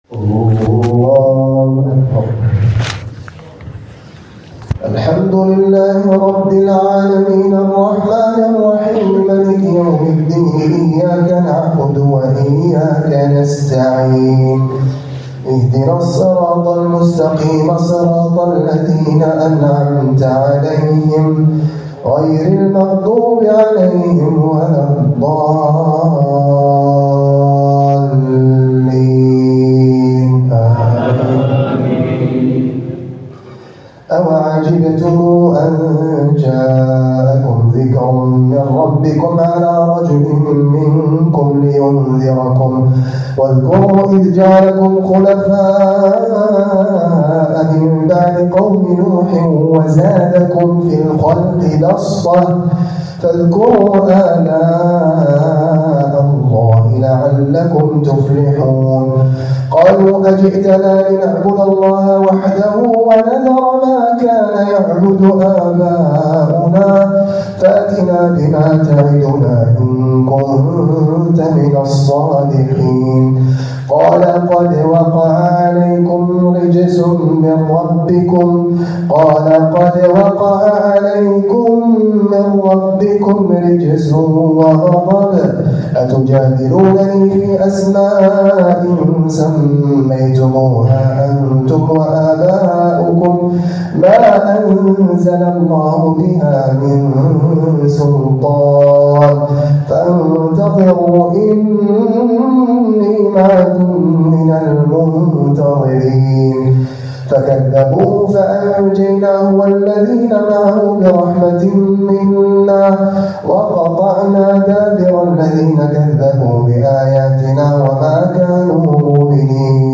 جديد - تلاوتي بالبيات مما تيسر من الأعراف.من الليلة التاسعة لرمضان ١٤٤٦ه‍